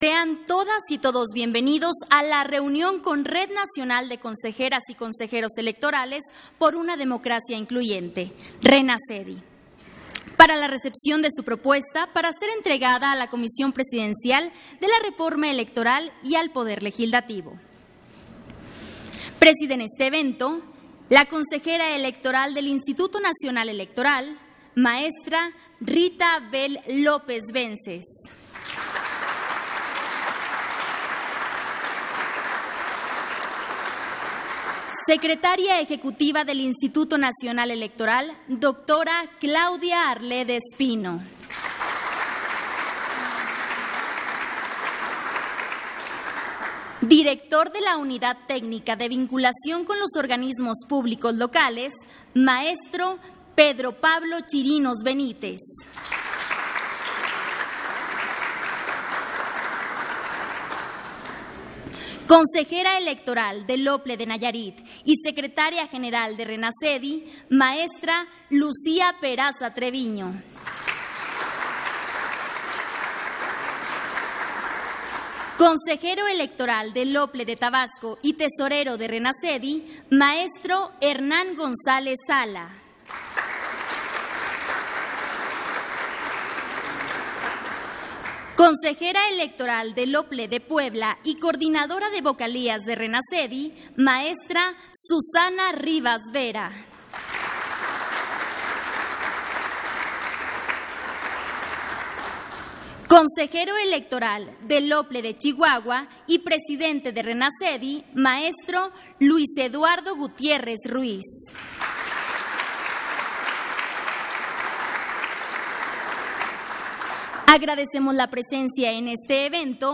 Audio de la reunión INE-RENACEDI, para la recepción de su propuesta, para ser entregada a la Comisión Presidencial para la Reforma electoral y al Poder Legislativo